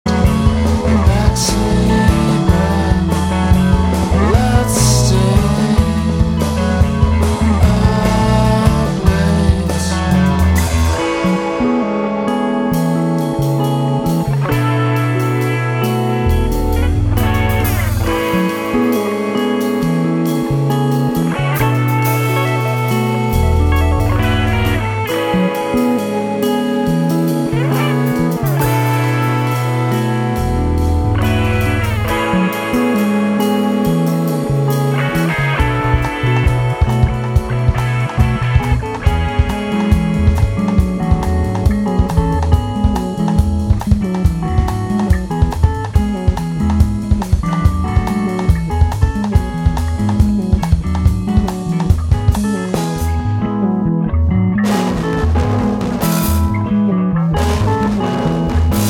Post Rock, Experimental Rock >
録音状態の関係か、全体的に線が細くて音全体も必要以上にぼやけてしまっている印象もありますが